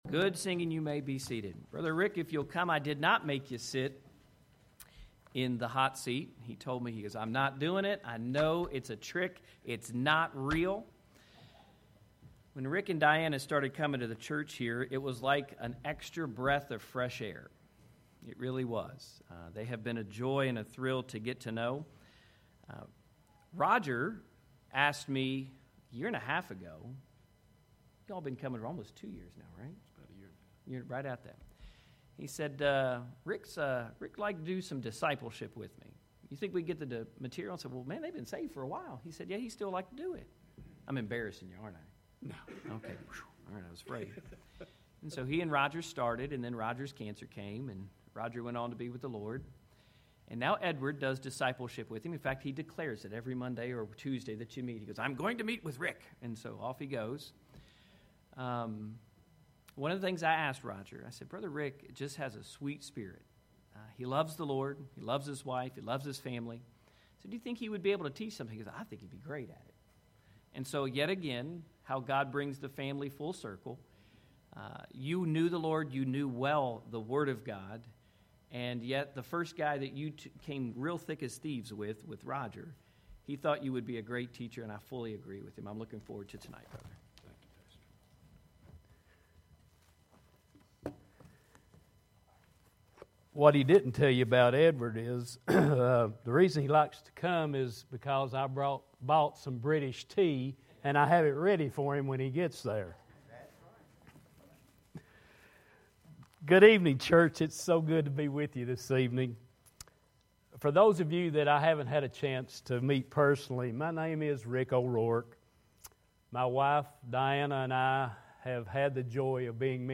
Sermons by Bluegrass Baptist Church